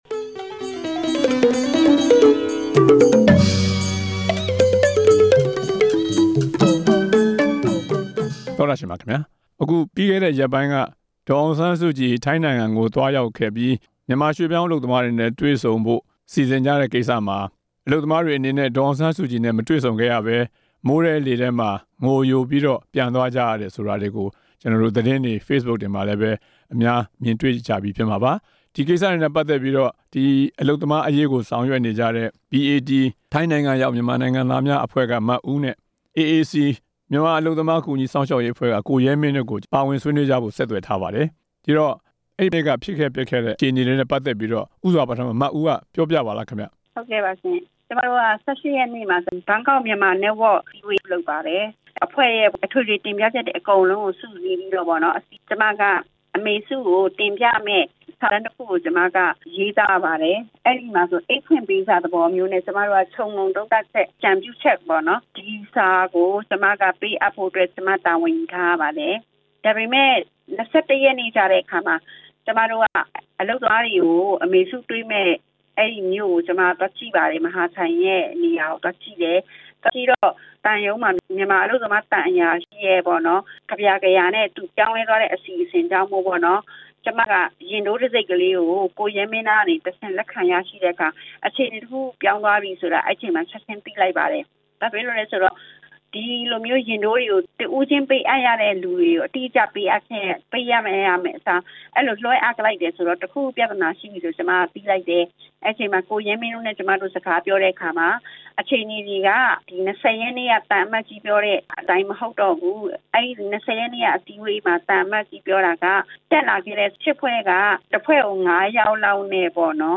ထိုင်းနိုင်ငံက မြန်မာ အလုပ်သမားအရေး ကူညီသူတွေနဲ့ ဆွေးနွေးချက်